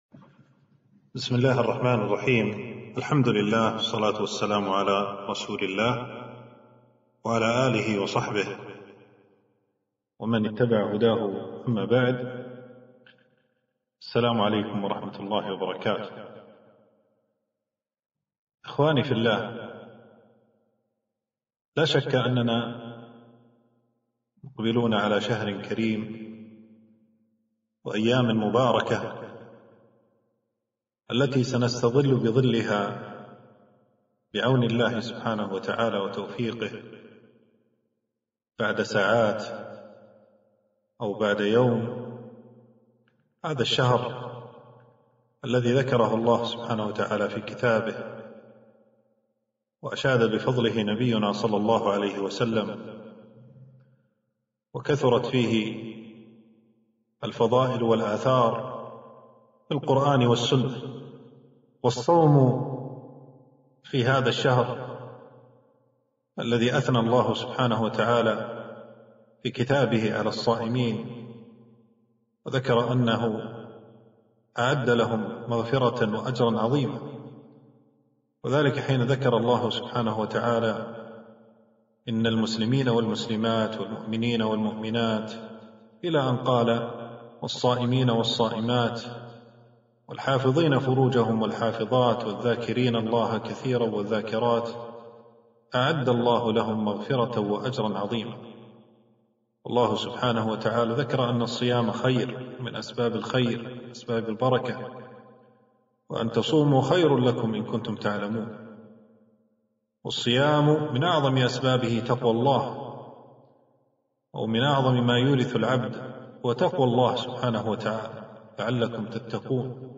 محاضرة - رمضان فضله وبعض أحكامه